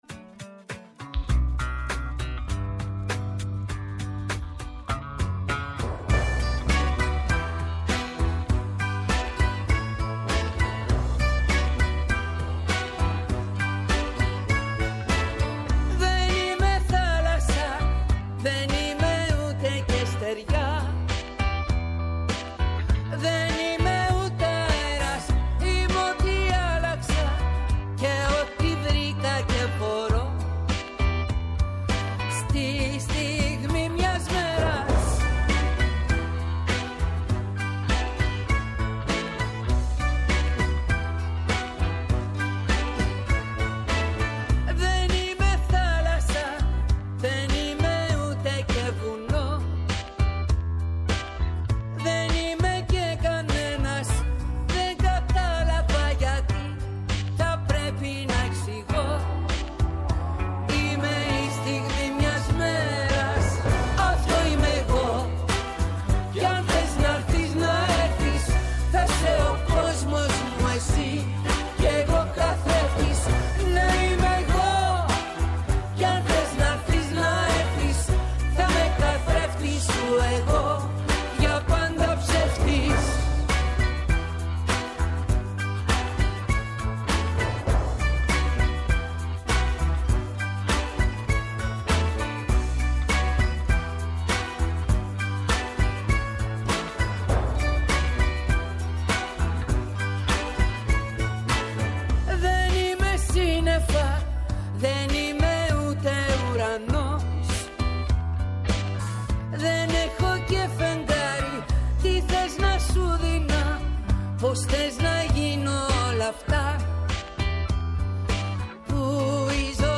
Καλεσμένος στο στούντιο